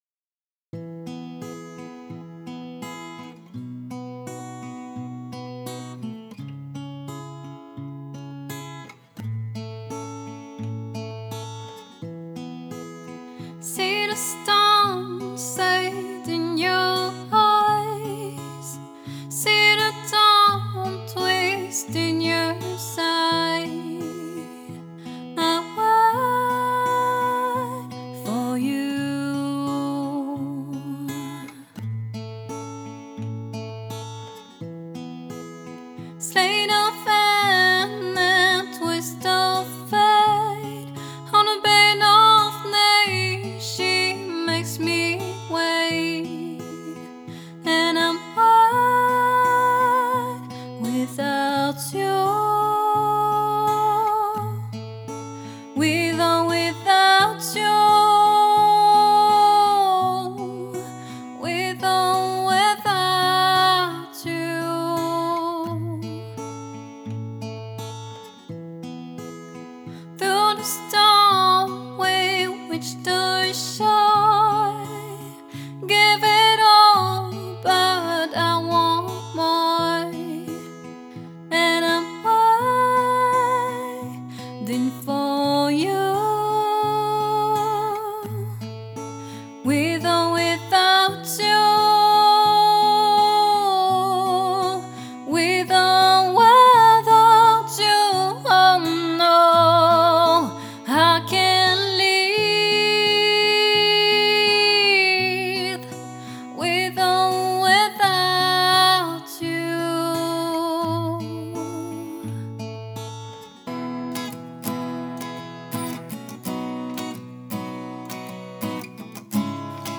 Démos audio cérémonies